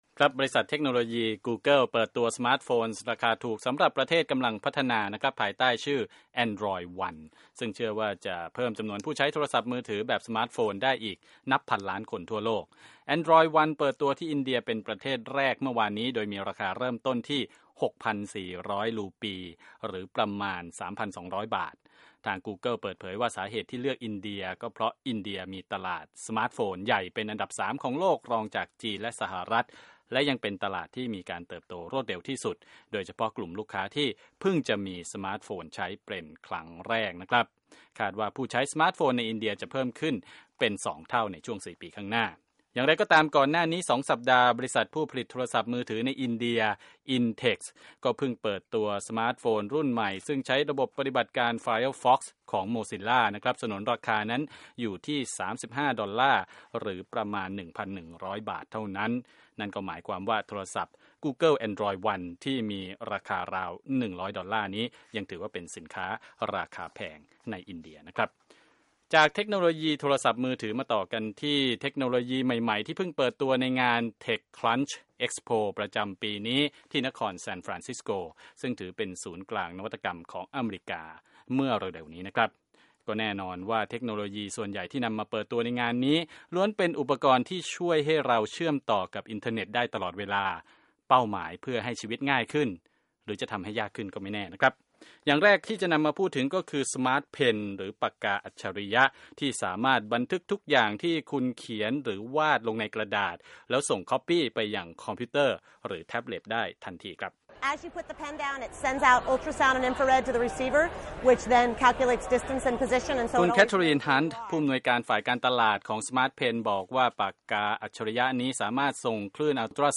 Tech News